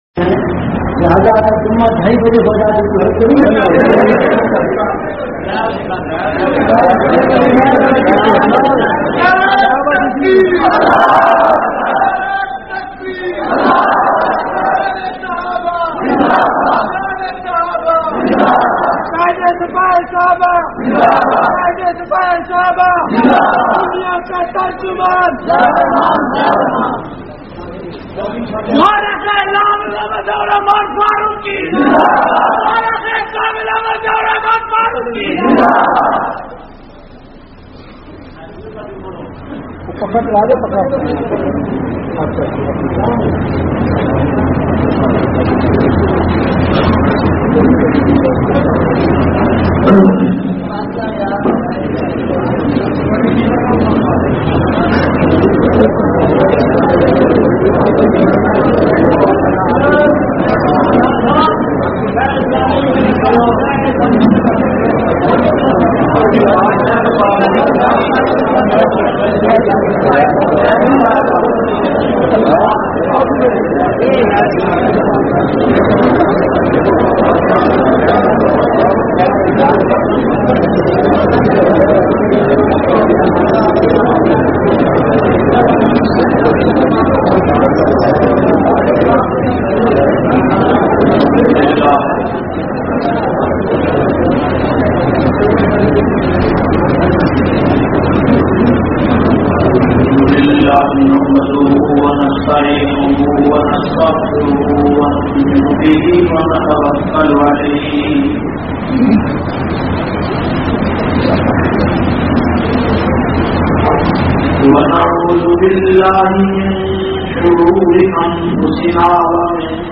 158- Shan e Risalat s.a.w.wKhutba e jumma wah cantt.mp3